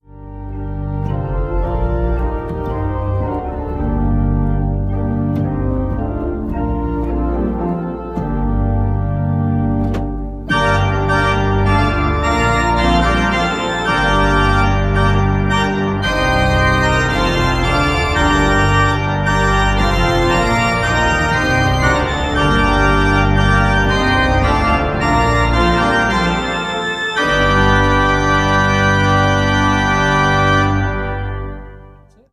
5657 Organ Speed